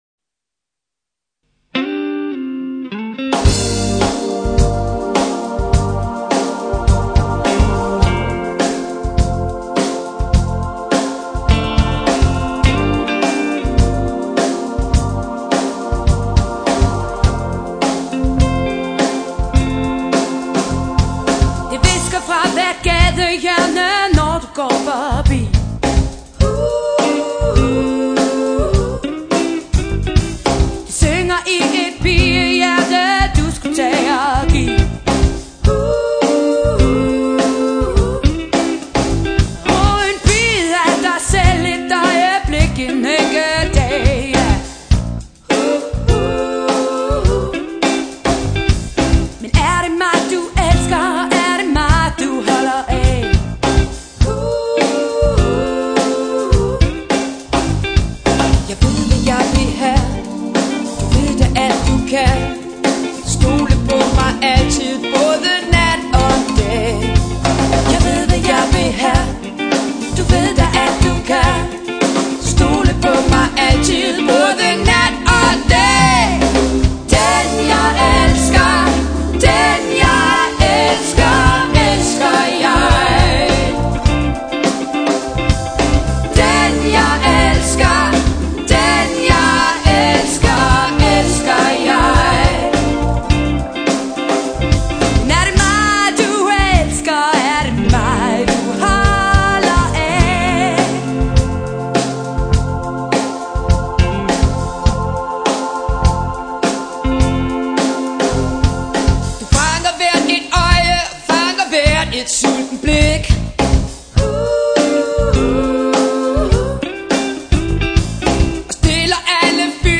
Kopiband i topklasse - de største hits fra 80'erne og frem.
vokal
guitar
keys
trommer